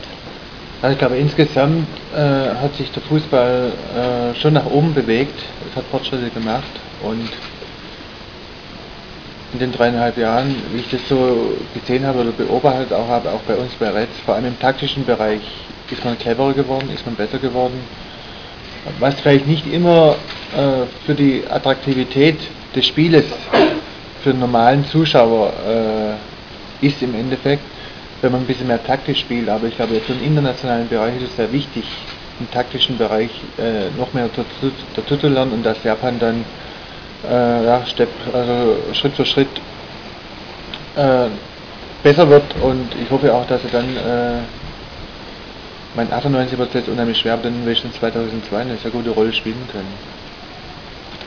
ブッフバルト選手退団会見！
マークはギドの肉声が聞けます！